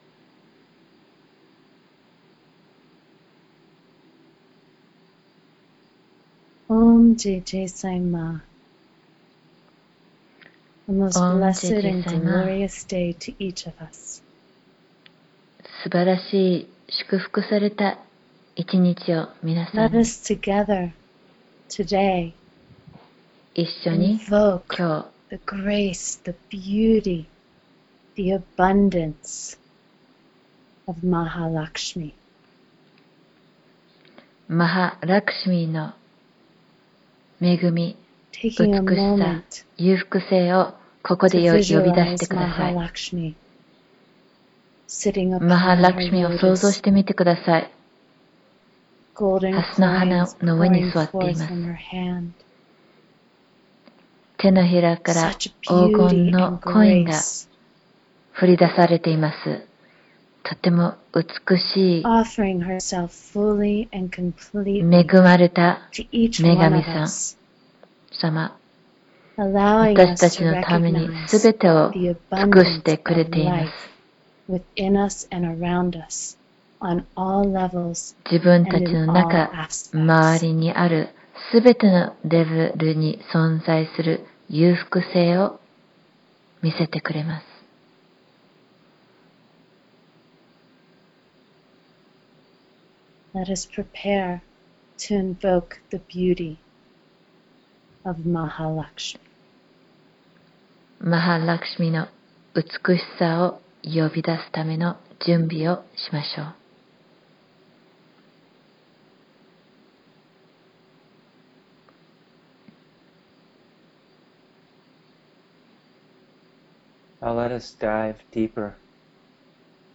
サイマーのプラマチャーリ（僧尼）が録音した誘導瞑想を 以下からお聴きになるか、 こちら からダウンロードしていただけます。
ラクシュミの瞑想.mp3